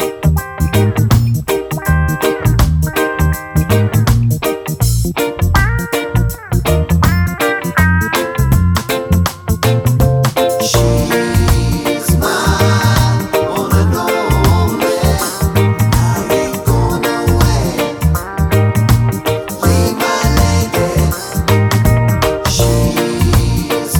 no Backing Vocals Reggae 4:39 Buy £1.50